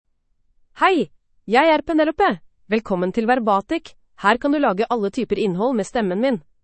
Penelope — Female Norwegian Bokmål AI voice
Penelope is a female AI voice for Norwegian Bokmål (Norway).
Voice sample
Listen to Penelope's female Norwegian Bokmål voice.
Penelope delivers clear pronunciation with authentic Norway Norwegian Bokmål intonation, making your content sound professionally produced.